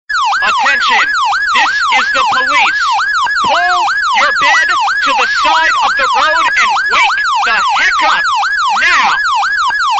Polis Sireni, Android, Sirens